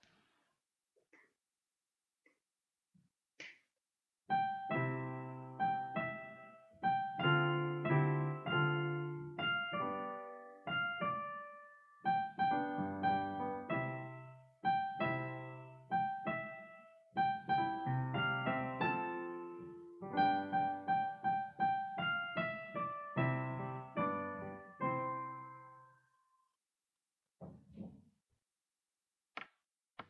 Votre mission, si vous l’acceptez , sera de faire réviser les paroles des chansons grâce à leur musique instrumentale.
L’accompagnement et la a mélodie sont joués au piano pour faciliter le chant.